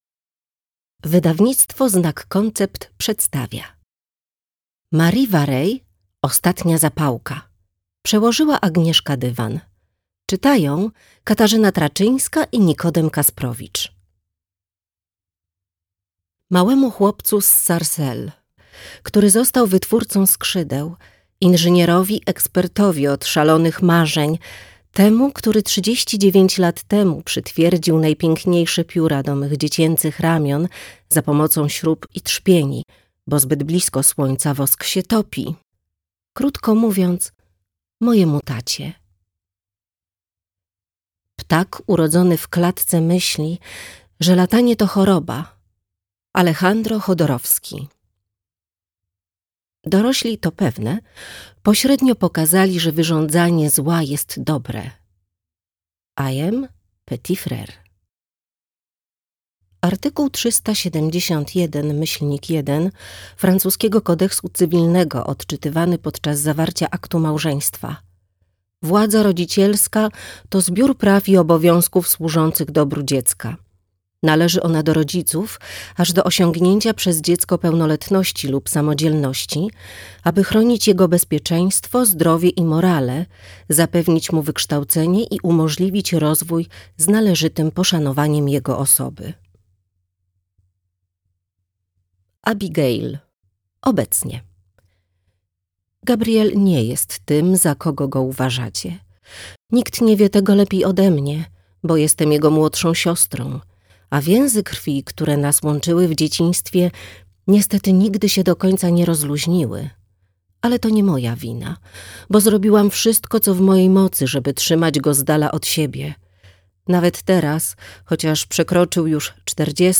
Ostatnia zapałka - Marie Vareille - audiobook + książka